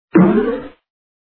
toonhit.wav